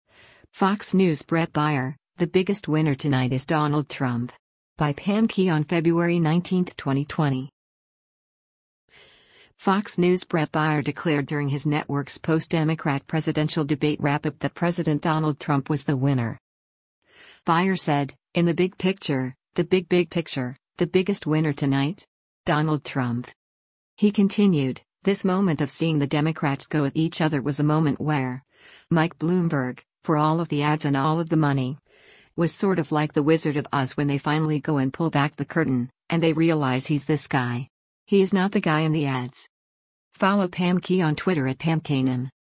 Fox News’ Bret Baier declared during his network’s post-Democrat presidential debate wrap-up that President Donald Trump was the winner.